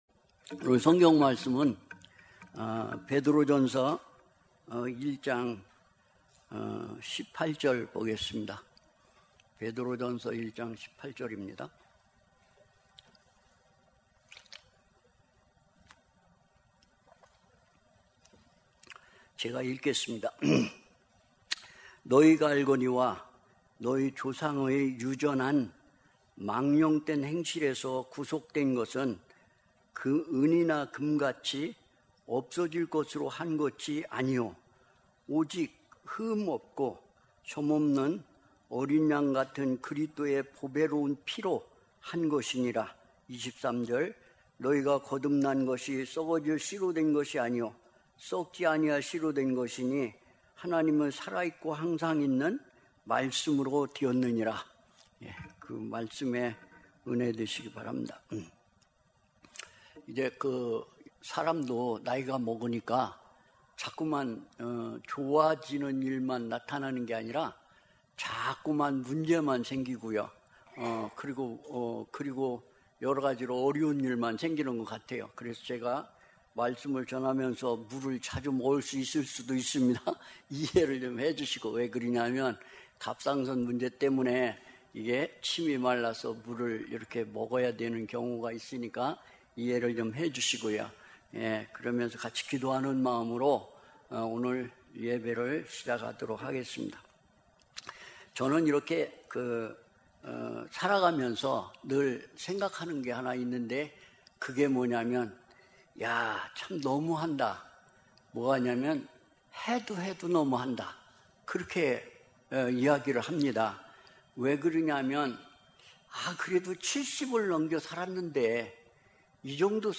주일예배 - 베드로전서 1장 18, 19, 23절